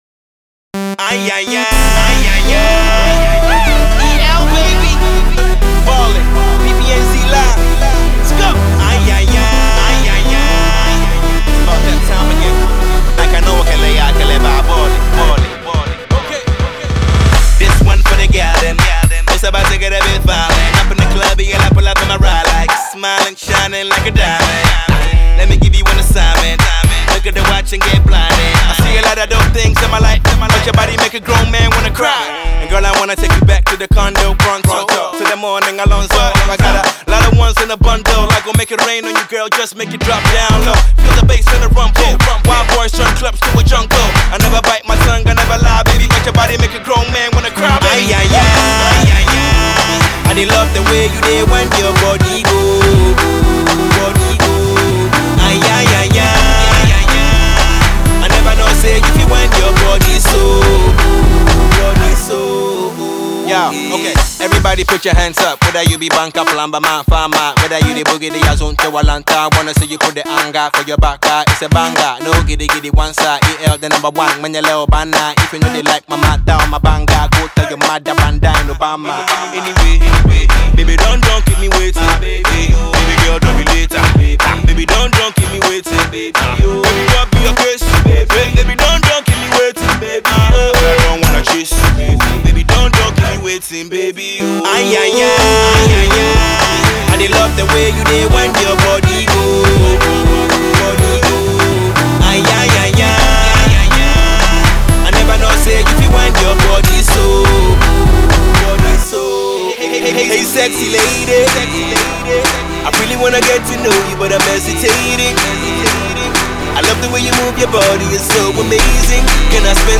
Another party track for charts.